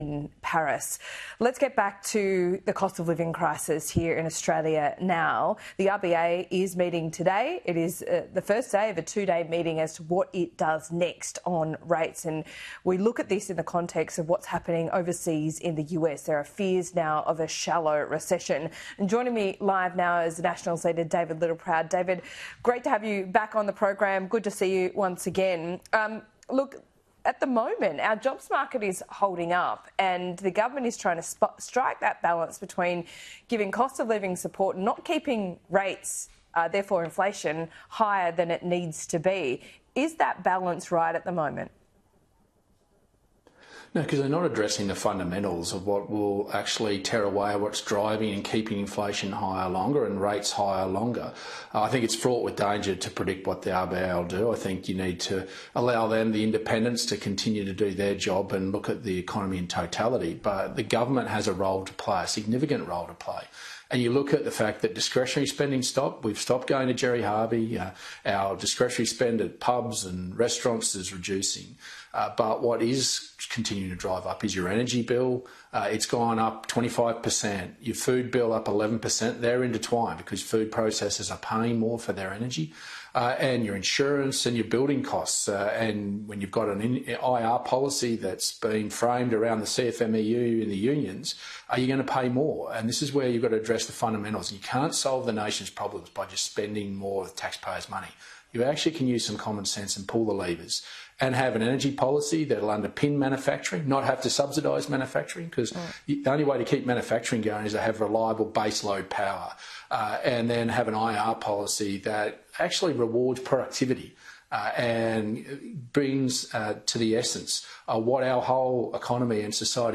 Leader of The Nationals Transcript - Laura Jayes, AM Agenda - 5 August 2024 - eLanka